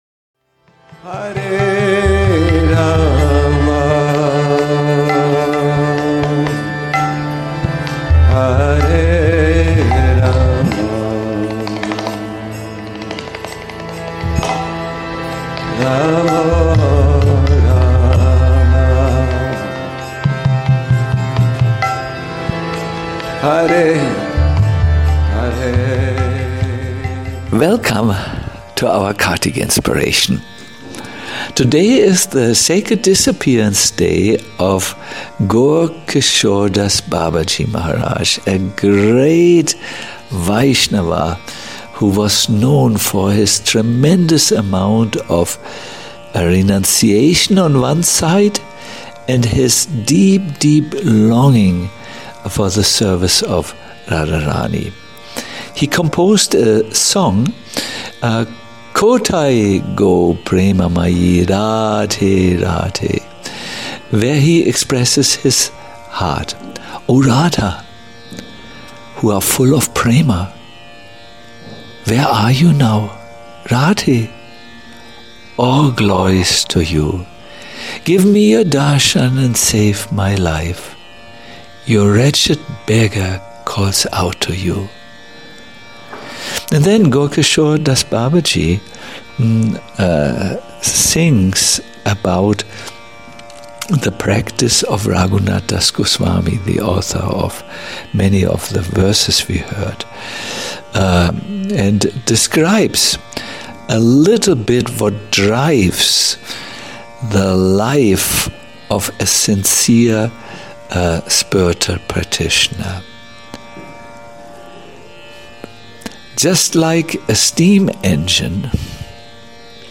Kartik Inspirations 27 - Where are You Radharani - a lecture